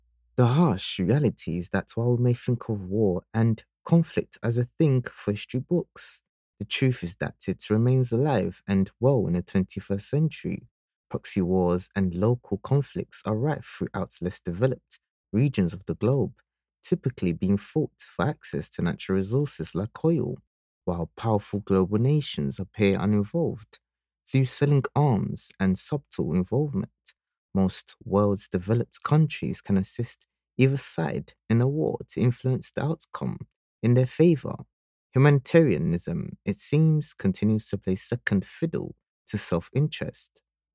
Training Video Voices
English (Caribbean)
Yng Adult (18-29) | Adult (30-50)